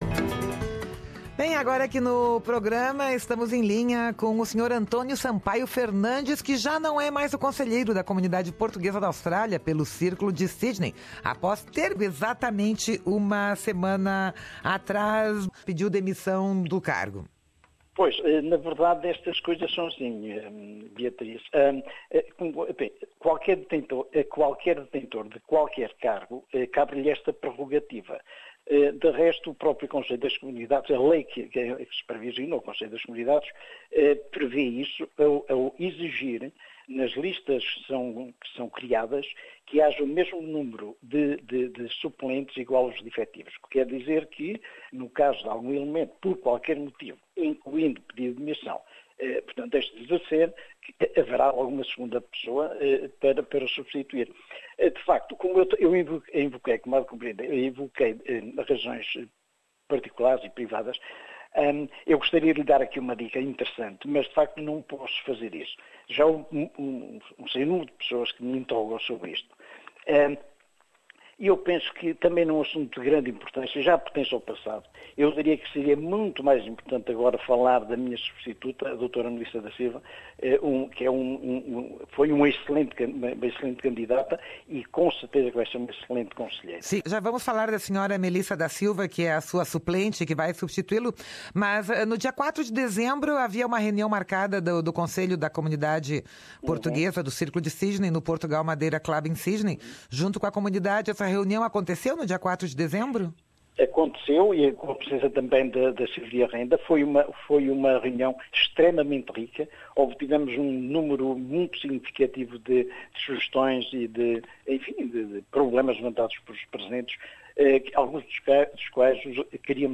Em entrevista à Rádio SBS